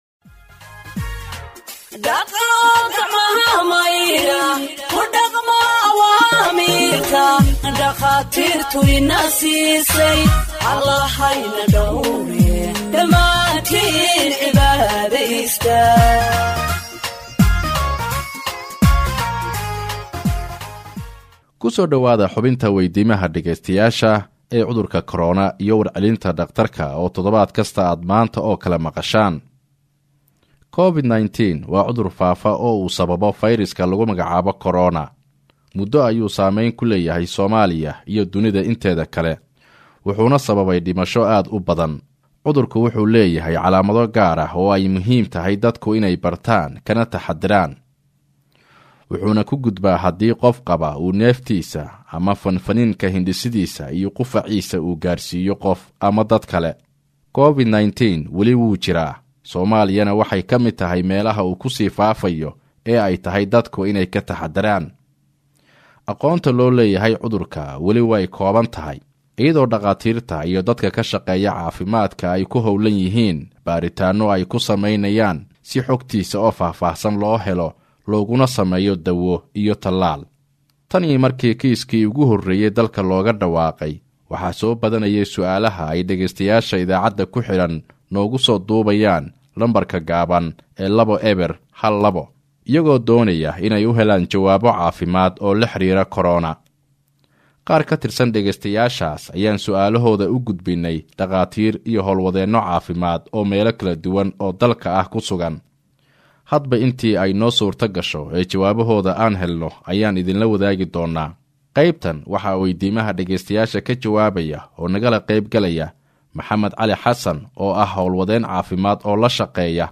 Health expert answers listeners’ questions on COVID 19 (39)